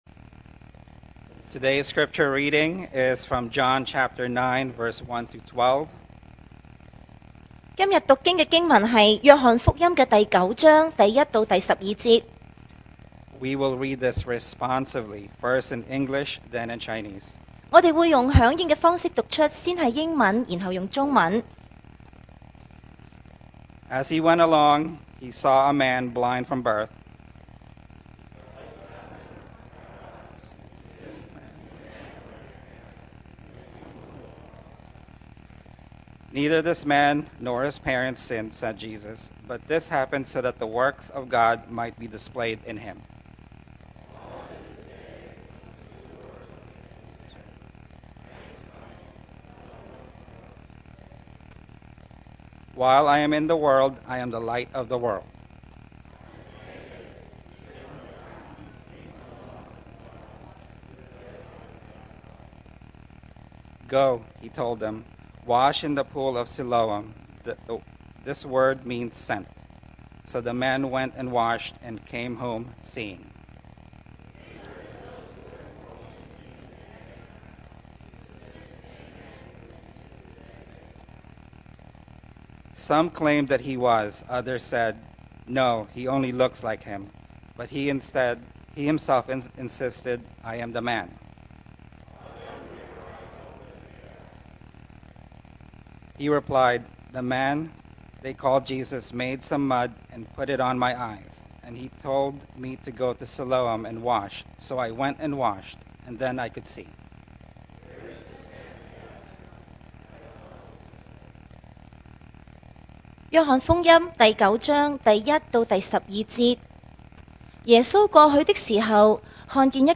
Passage: John 9:1-12 Service Type: Sunday Morning